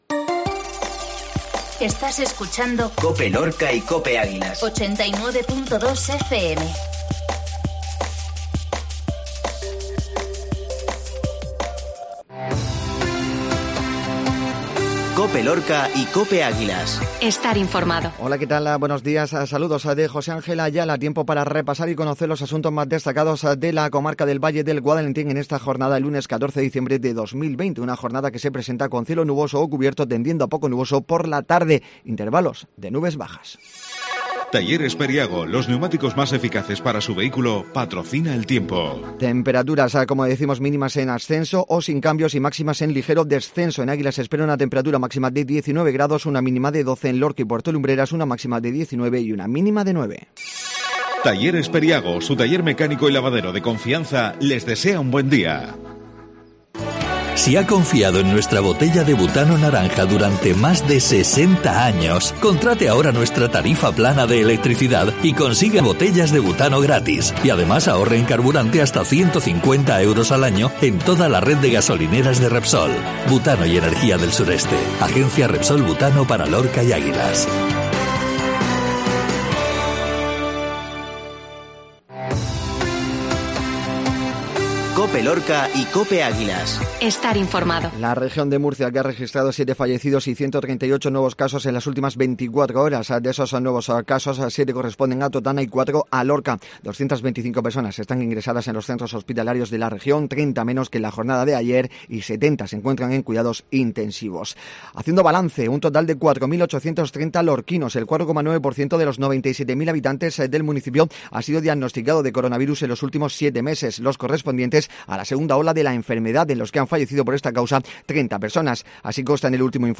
INFORMATIVO COPE LORCA LUNES